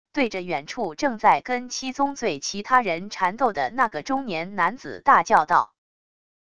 对着远处正在跟七宗罪其他人缠斗的那个中年男子大叫道wav音频生成系统WAV Audio Player